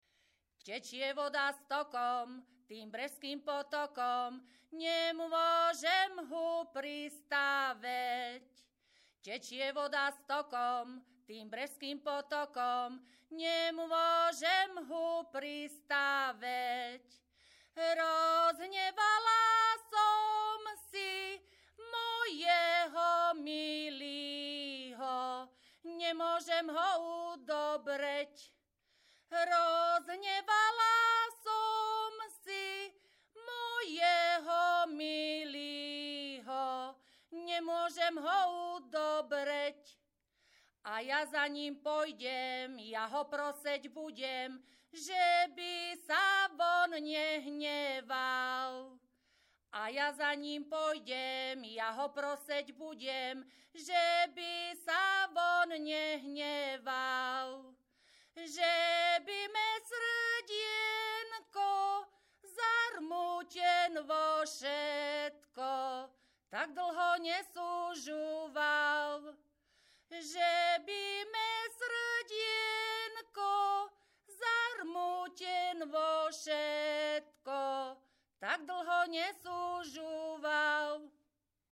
Descripton sólo ženský spev bez hudobného sprievodu
Place of capture Brehy
Key words ľudová pieseň